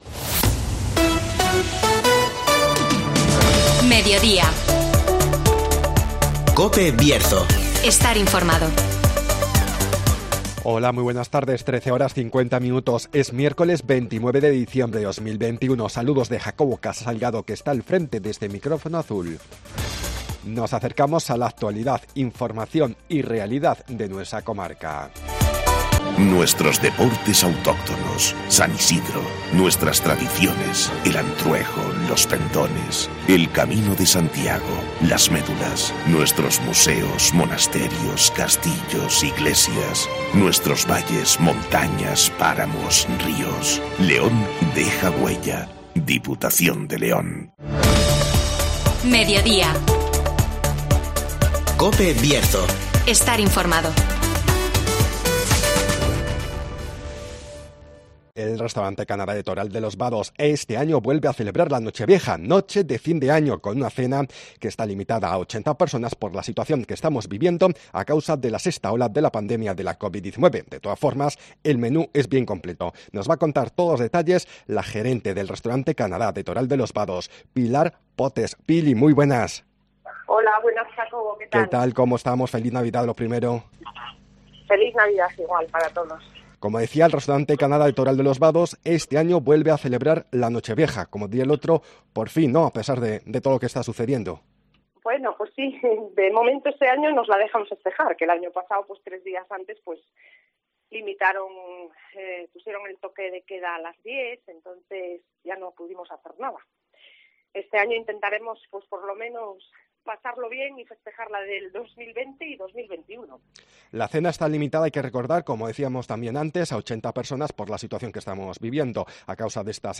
El Restaurante Canada de Toral de los Vados este año vuelve a celebrar la Nochevieja (Entrevista